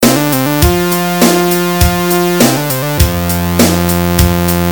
It was a funky little number.
It had a horn section, and some funk guitar played by an individual that had the kind of soul that most of us can only dream about.
But there was also a haunting riff played over and over by the horn section, and since that day I've been unable to get it out of my head.
I know, it's a sad, sad reproduction, but if anyone can help me identify this song, I promise them big big things.
mystery_song.mp3